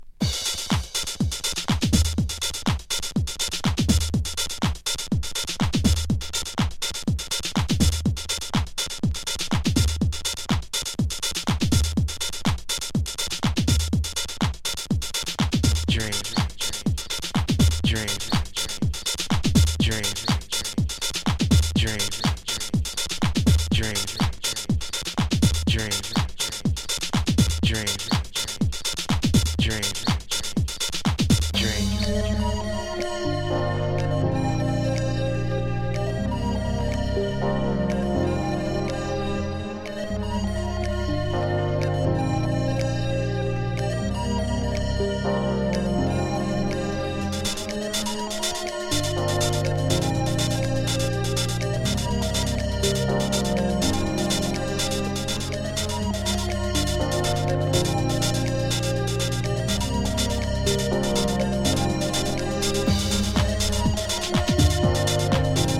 新入荷からTechno～House～Drum’n’Bassなどなど！
狂気のDeep Dub House！
ウワモノのサンプリングと、太すぎるビートの組み合わせがたまらないです。
トランシーなシンセと、跳ねたビートがナイスなB-1ぜひ。